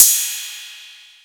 Crashes & Cymbals
Metro Delay Cymbal.wav